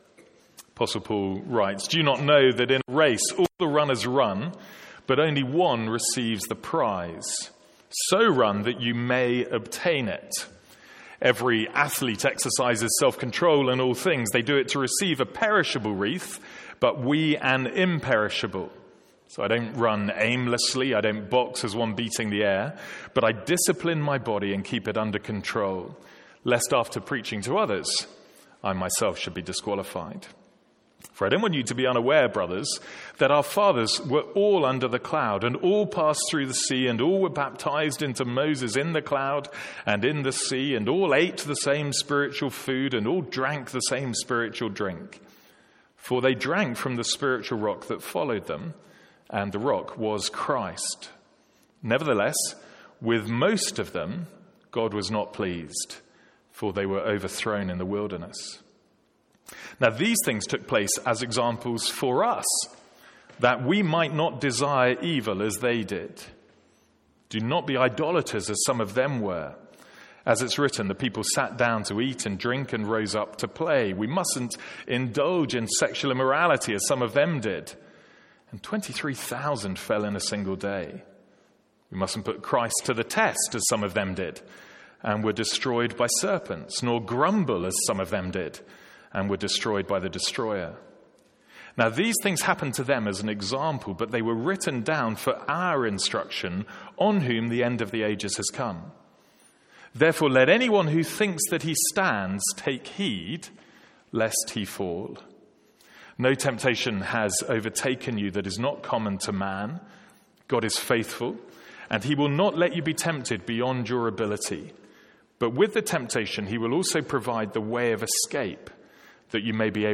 Sermons | St Andrews Free Church
Reading starts at 1 Corinthians 9:24.